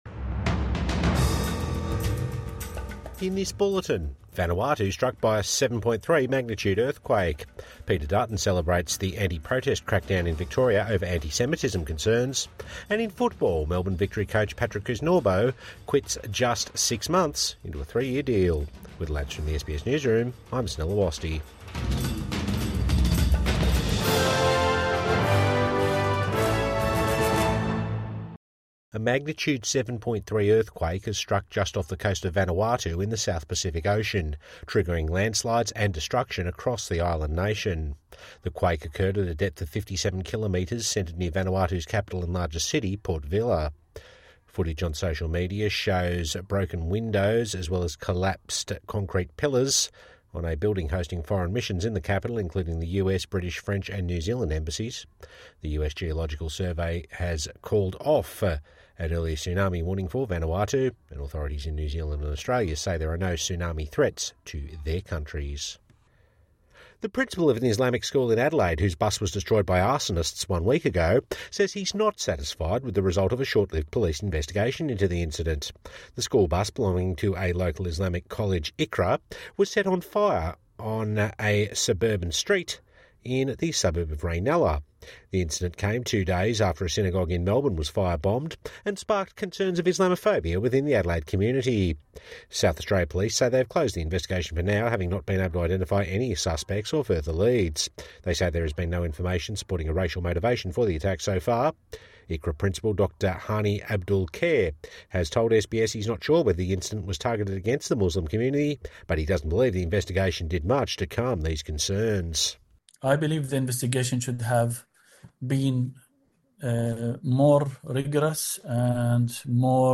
Evening News Bulletin 17 December 2024